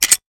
weapon_foley_pickup_19.wav